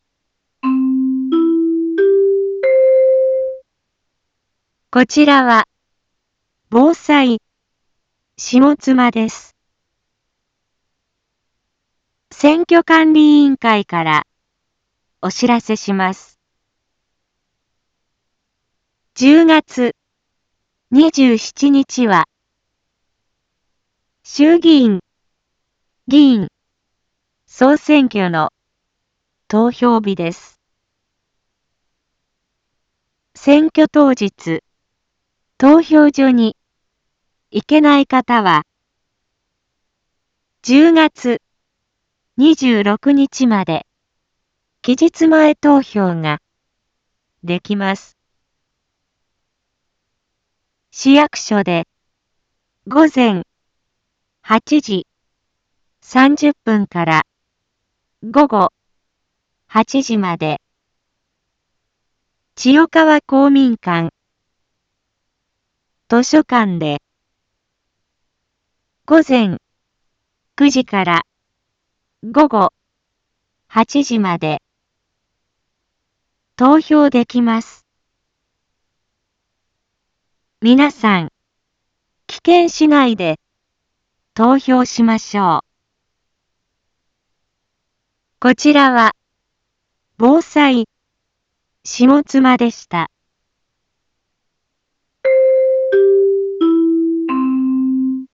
一般放送情報
Back Home 一般放送情報 音声放送 再生 一般放送情報 登録日時：2024-10-24 18:31:43 タイトル：衆議院議員総選挙の啓発（期日前投票期間） インフォメーション：こちらは、ぼうさいしもつまです。